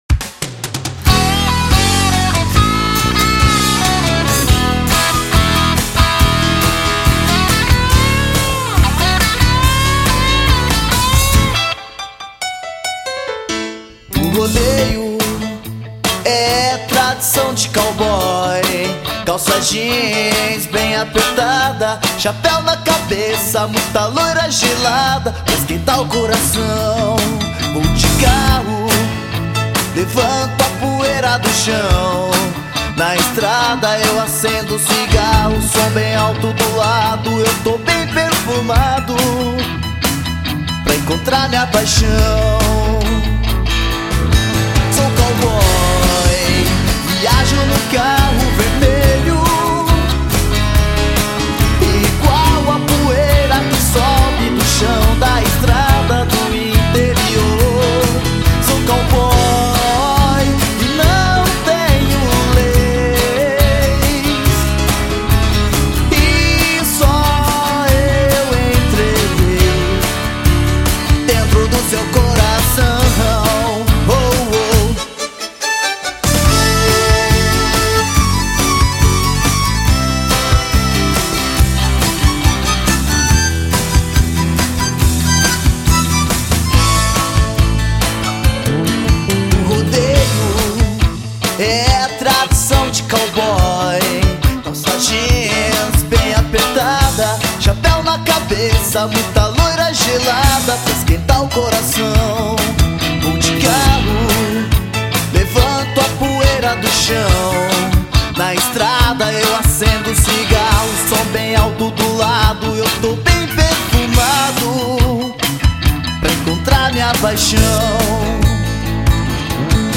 EstiloCountry